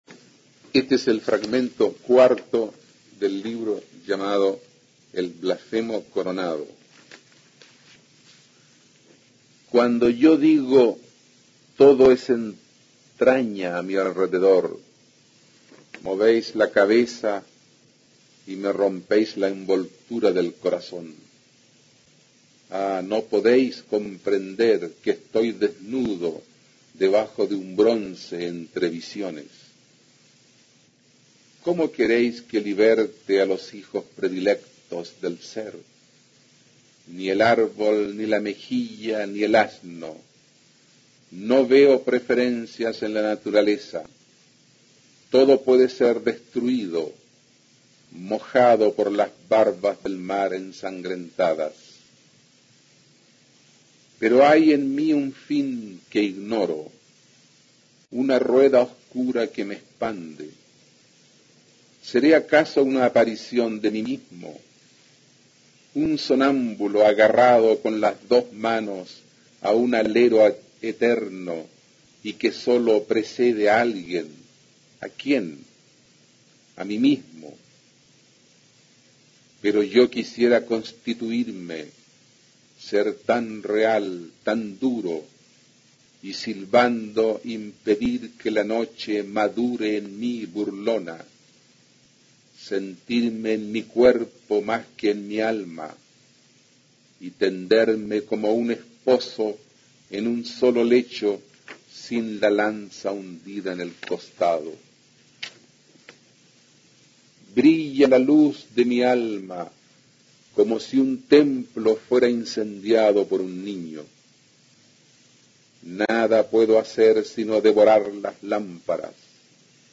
A continuación se puede escuchar a Humberto Díaz-Casanueva, destacado autor de las vanguardias hispanoamericanas y Premio Nacional de Literatura en 1971, leyendo el fragmento cuarto de su libro "El blasfemo coronado" (1940).
Poema